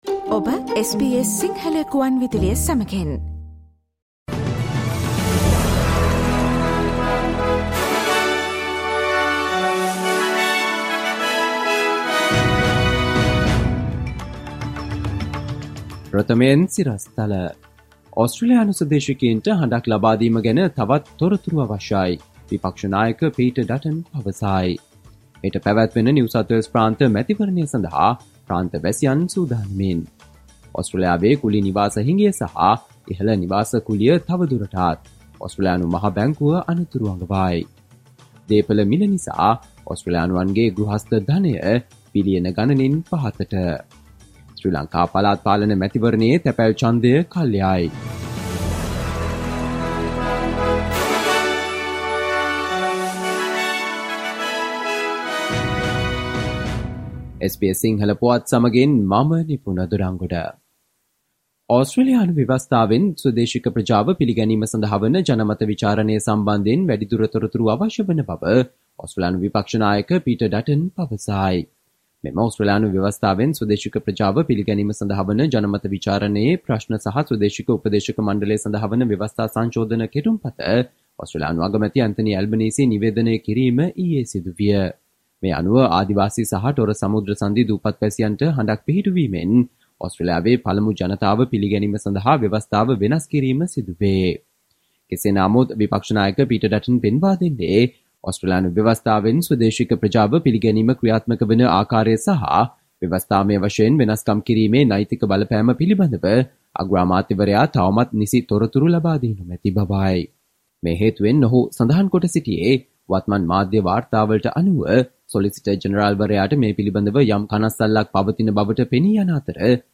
Listen to the latest news from Australia, across the globe, and the latest news from the sports world on SBS Sinhala radio news – Friday, 24 March 2023.